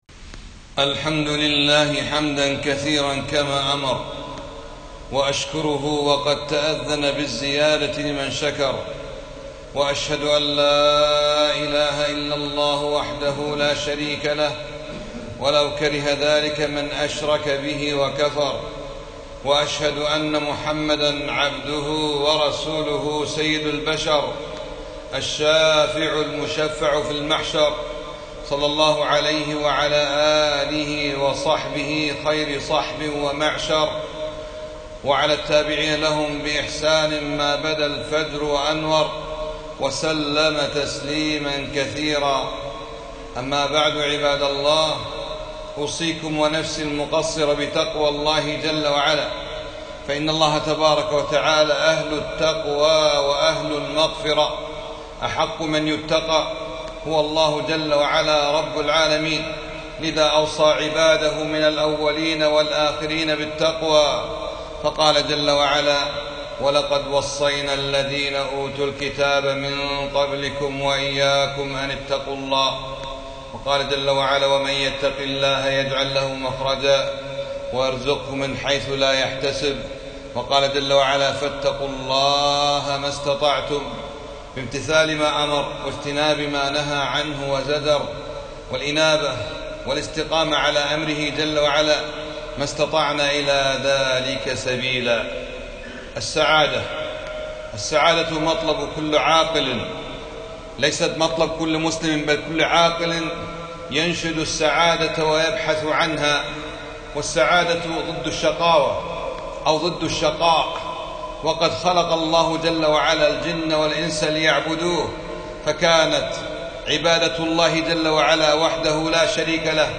خطبة - اعظم أسباب السعادة تحقيق التوحيد